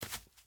Minecraft Version Minecraft Version 1.21.5 Latest Release | Latest Snapshot 1.21.5 / assets / minecraft / sounds / mob / turtle / baby / shamble2.ogg Compare With Compare With Latest Release | Latest Snapshot
shamble2.ogg